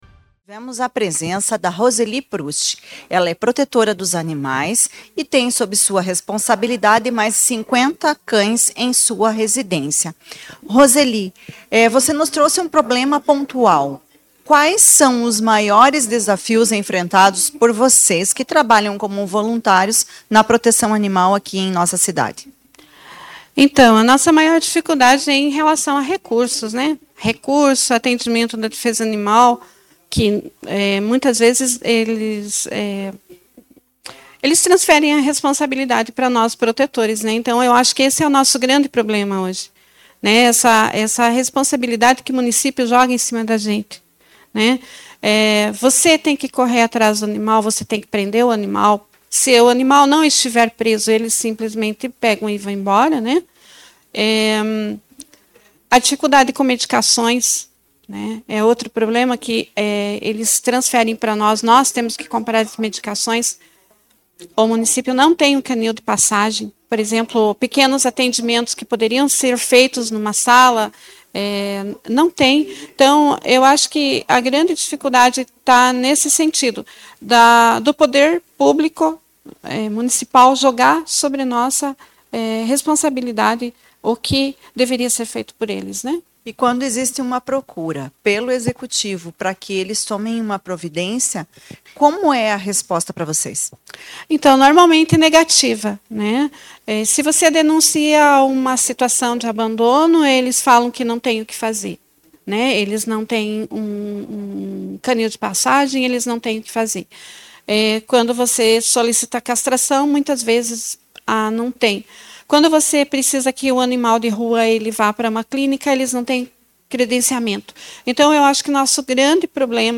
A vereadora expôs as dificuldades enfrentadas, a ausência de atendimento de saúde veterinária assim como locais adequados de abrigos aos cães. Em entrevista ela disse que o castramóvel encontra-se parado e neste momento basicamente somente o trabalho das voluntárias protetoras é que são contados por estes animais que necessitam de cuidados.